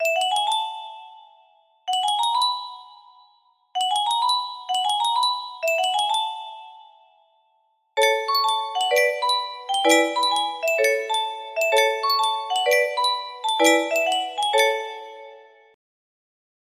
BPM 96